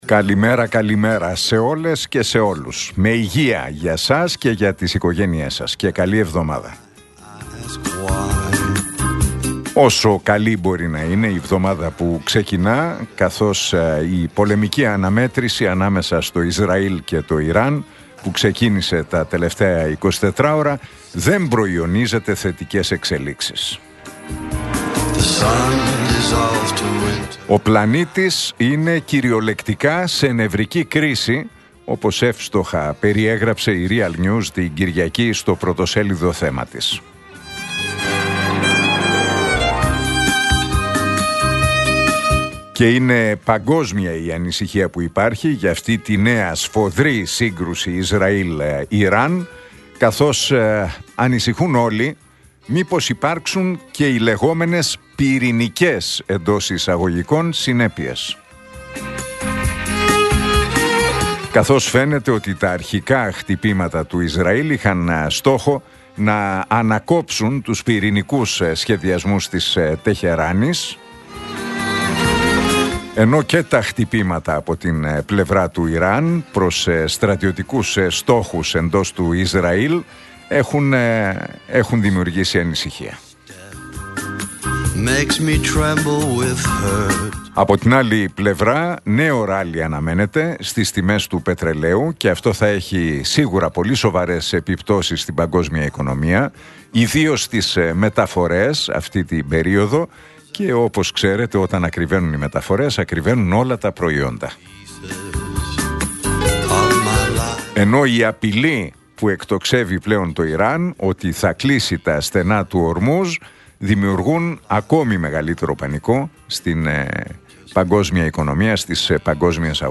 Ακούστε το σχόλιο του Νίκου Χατζηνικολάου στον ραδιοφωνικό σταθμό Realfm 97,8, την Δευτέρα 16 Ιουνίου 2025.